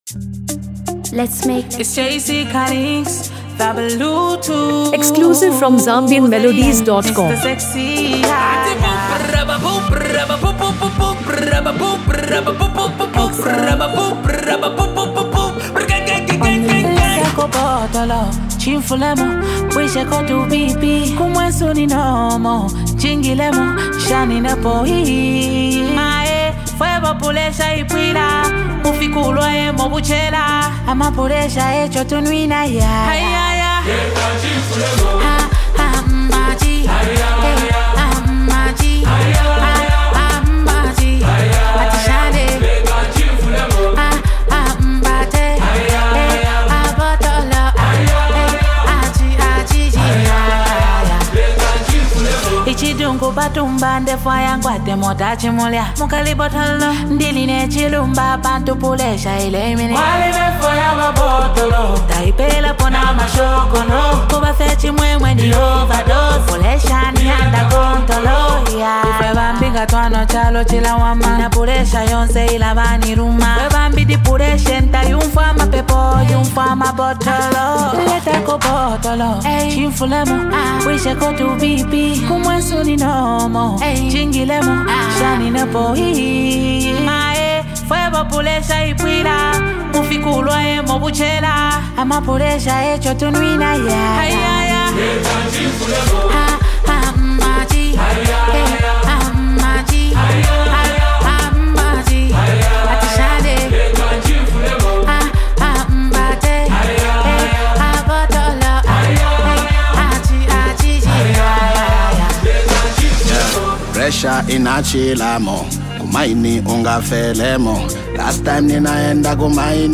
vibrant Zambian Afrobeat jam
Genre: Dancehall/Afro-beats